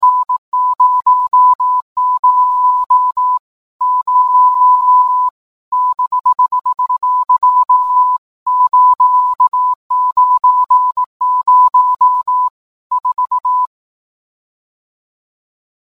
Es piept - hörst du es auch?